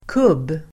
Uttal: [kub:]